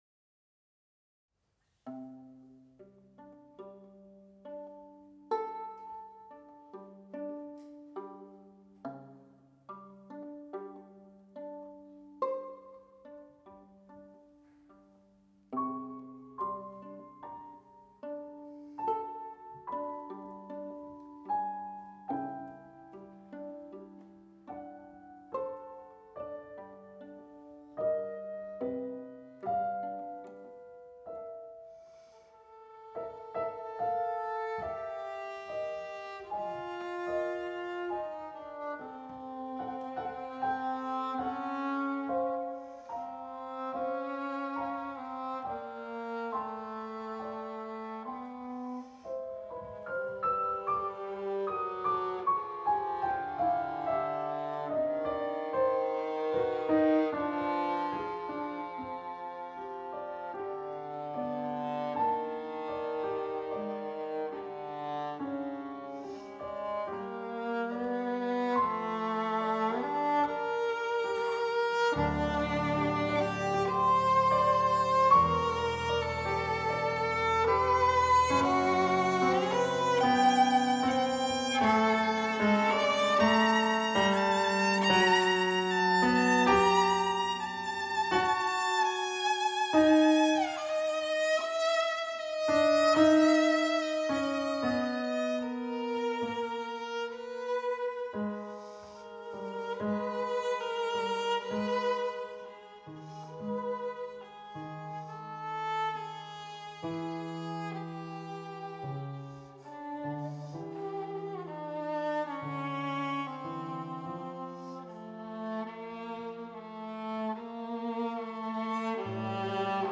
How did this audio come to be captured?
Venue: Bantry House